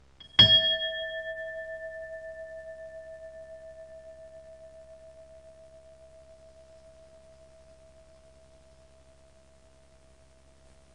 Indian brass pestle lo
ambience bell ding indian mortar noise pestle ring sound effect free sound royalty free Sound Effects